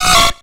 Cri de Fouinar dans Pokémon X et Y.